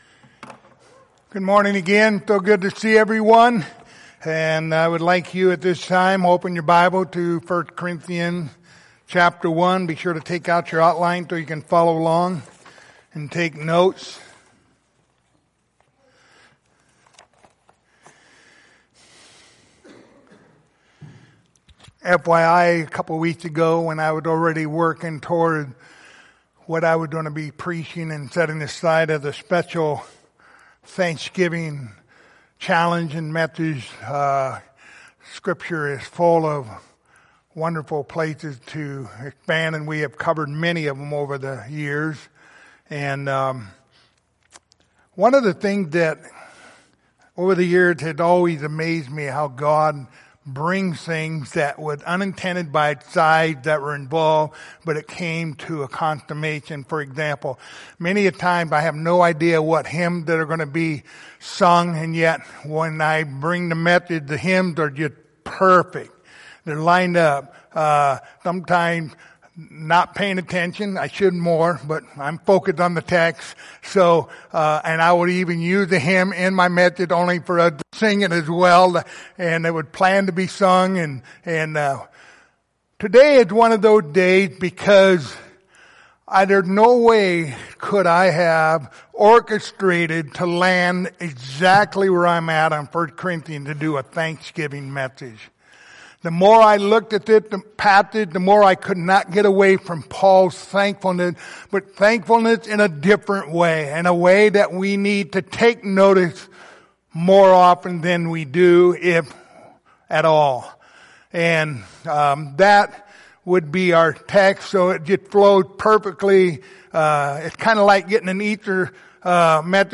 Passage: 1 Corinthians 1:4-9 Service Type: Sunday Morning